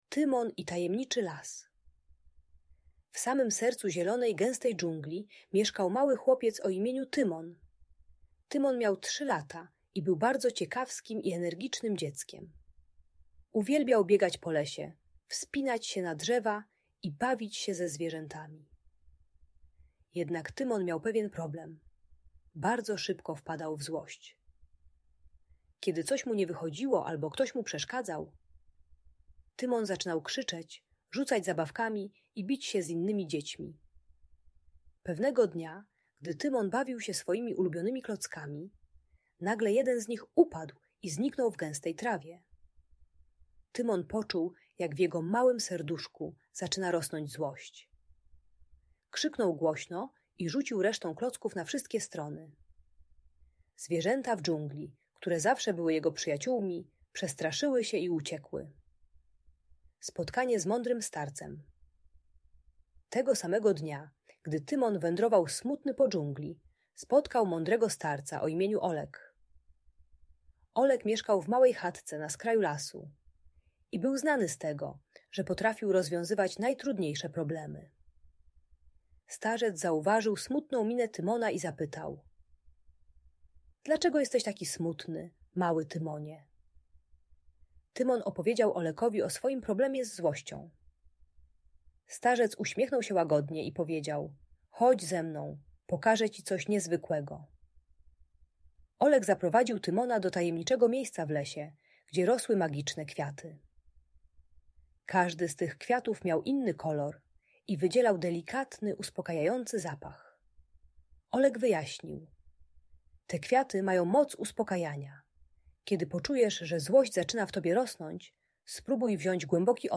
Opowieść o Tymonie i Tajemniczym Lesie - Audiobajka